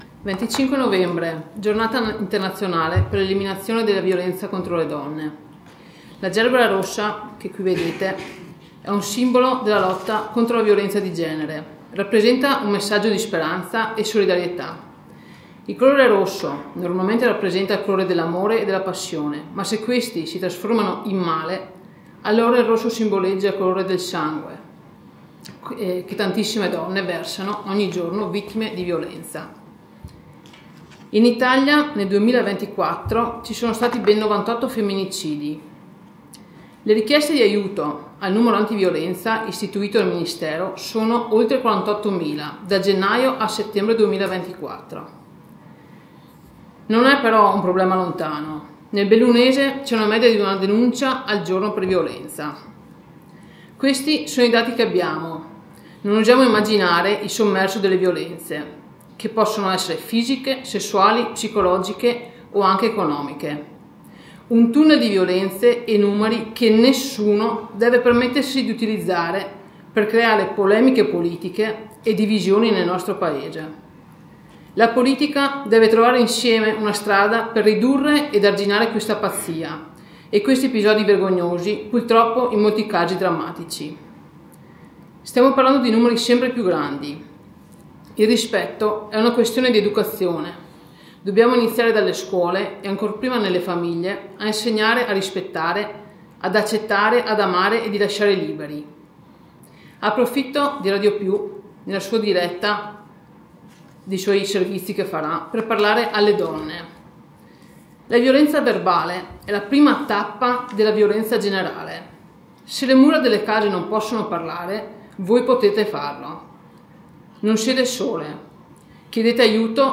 AGORDO L’intervento di Cristina Bien, assessore alla cultura e sicurezza del comune di Agordo, durante il consiglio comunale del 25 novembre.